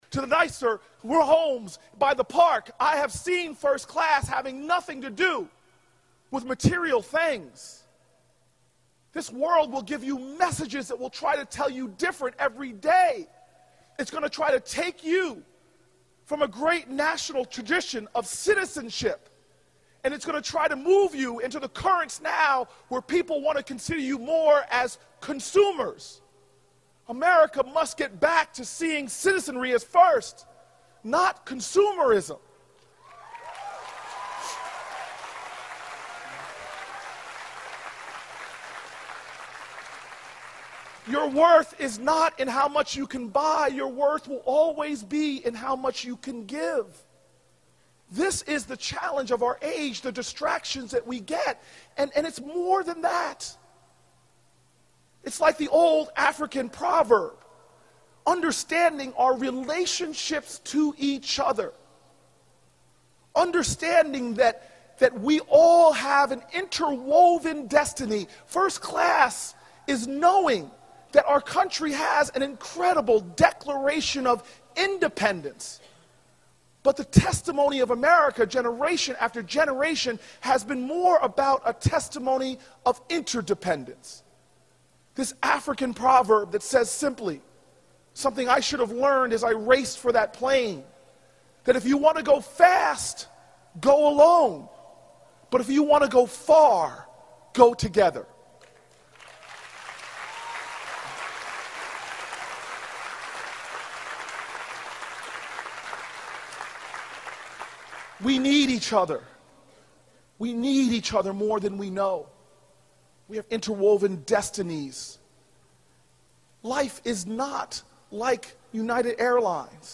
公众人物毕业演讲第444期:科里布克2013年耶鲁大学(12) 听力文件下载—在线英语听力室